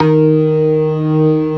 Index of /90_sSampleCDs/InVision Interactive - Keith Emerson Lucky Man/Partition F/ORGAN+SYNTH4